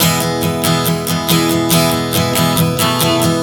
Strum 140 E 05.wav